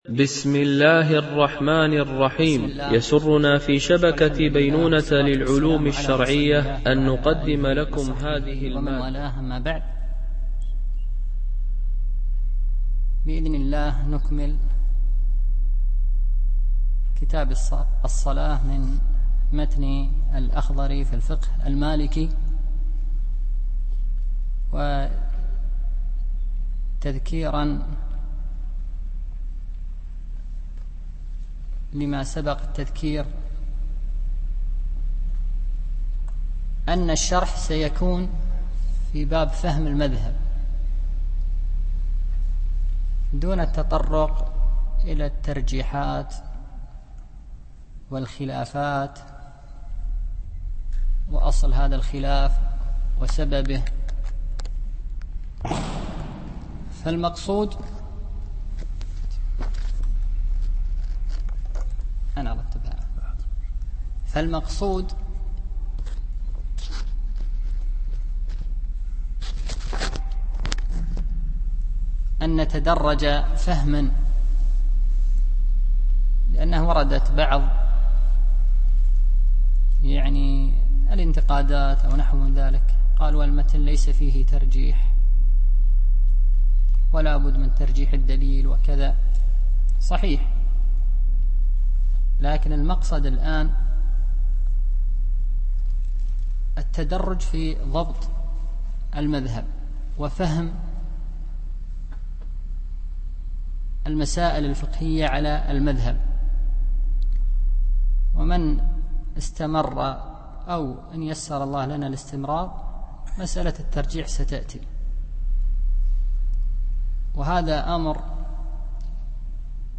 شرح الفقه المالكي ( المستوى الأول - متن الأخضري ) - الدرس 5